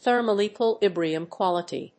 thermal+equilibrium+quality.mp3